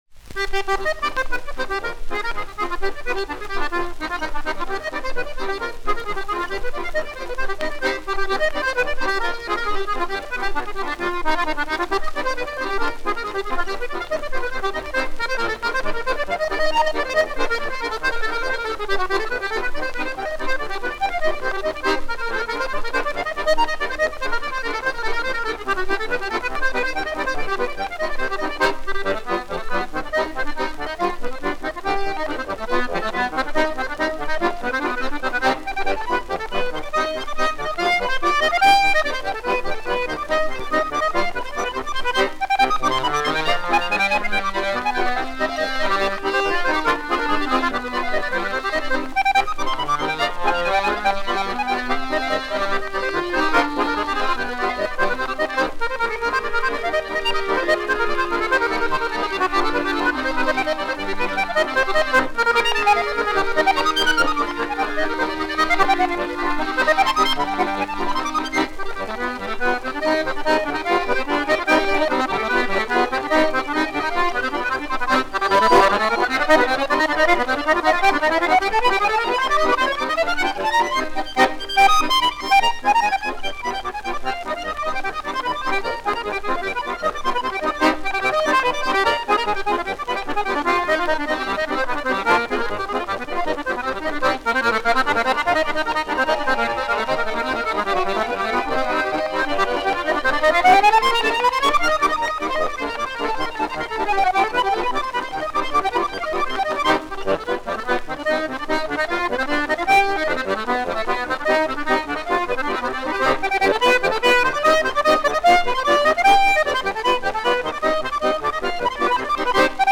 Описание:     Одна из немногочисленных записей первого трио баянистов.
Вот здесь настоящее трио